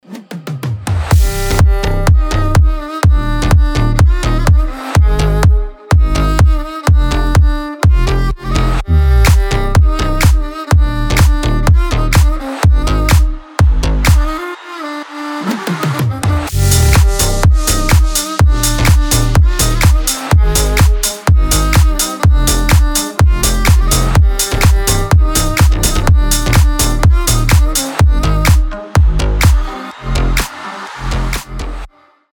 • Качество: 320, Stereo
deep house
мощные басы
без слов
восточные
дудук
slap house
Красивая восточная мелодия вкупе с мощным басом